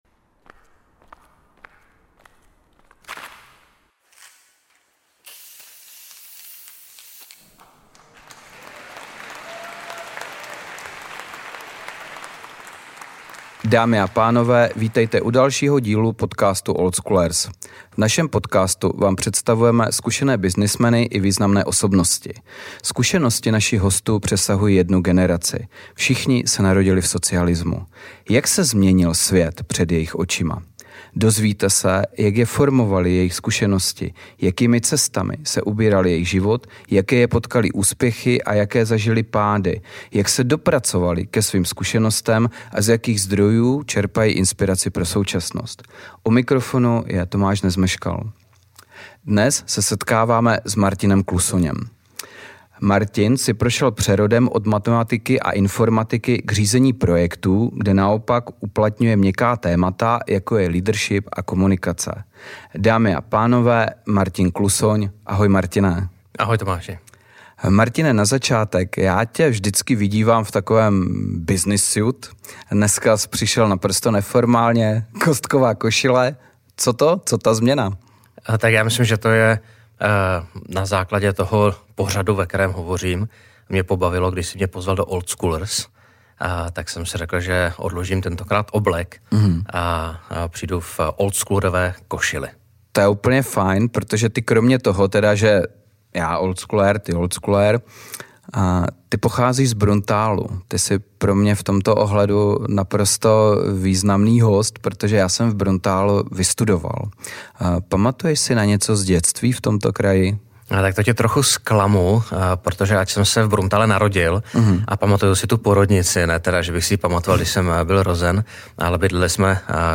Tento rozhovor půodně vznikl v roce 2021.